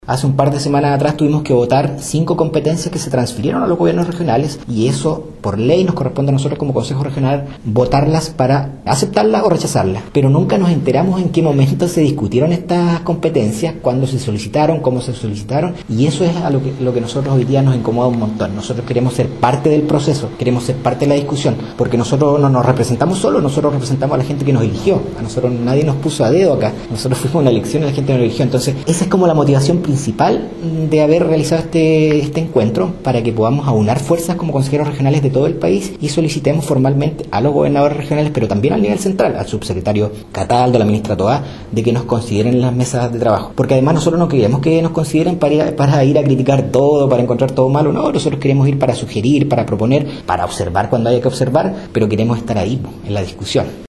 Para el Consejero Regional, Cesar Negrón, Presidente de la Comisión de Descentralización del Consejo Regional de los Lagos, el encuentro tuvo como objetivo unir criterios en torno al tema de la descentralización y como los consejeros deben tener una participación más activa en la discusión de las nuevas competencias de los gobiernos regionales: